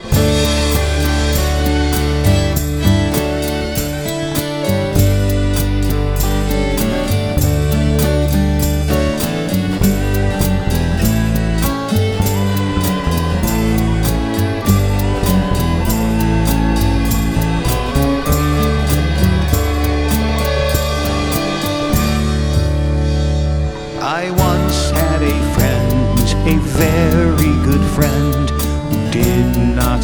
Penny Whistle, Bodhran
Acoustic guitar
Fiddle